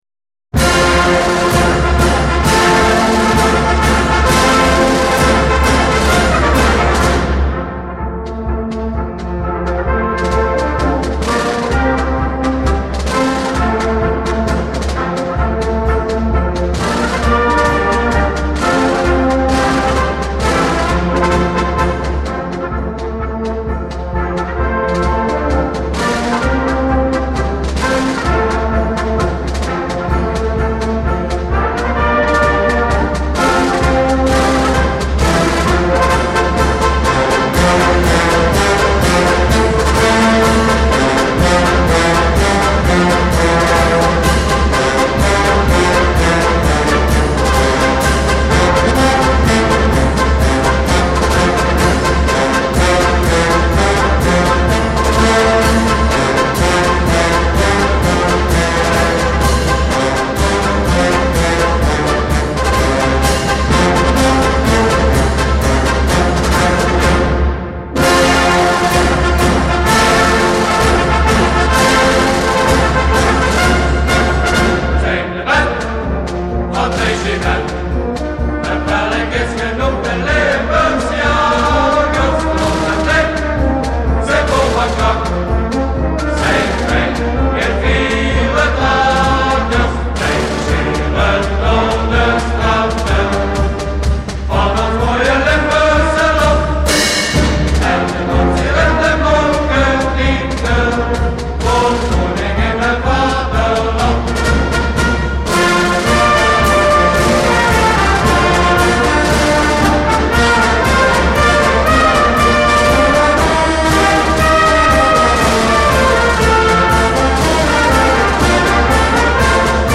Geluidsfragment van de Limburgse Jagersmars gespeeld door het Tamboer- en Fanfarekorps Regiment Limburgse Jagers.